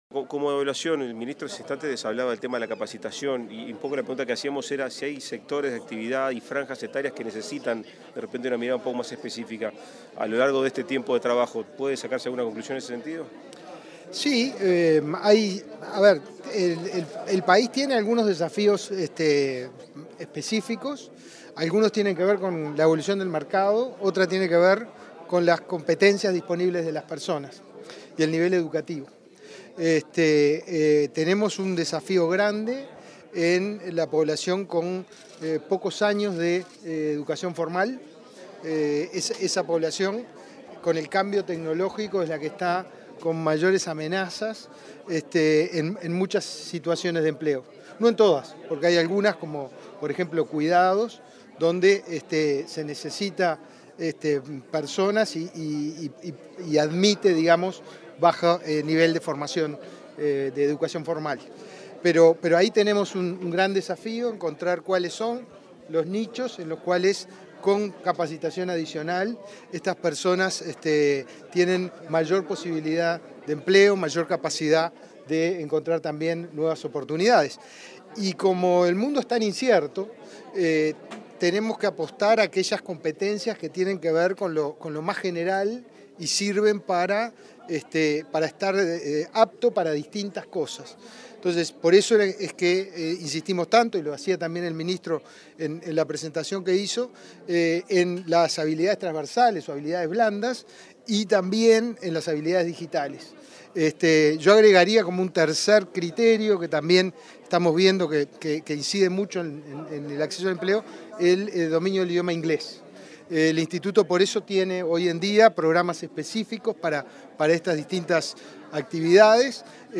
Declaraciones del director de Inefop, Pablo Darscht
Declaraciones del director de Inefop, Pablo Darscht 24/10/2023 Compartir Facebook X Copiar enlace WhatsApp LinkedIn Tras la celebración de los 15 años de creación del Instituto Nacional de Empleo y Formación Profesional (Inefop), este 24 de octubre, el director Pablo Darscht, realizó declaraciones a la prensa.